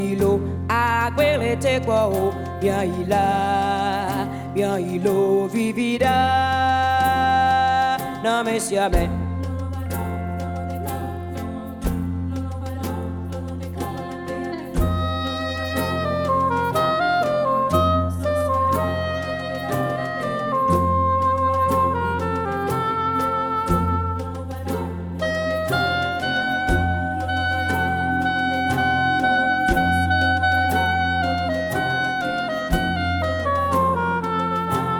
Жанр: Музыка мира
# World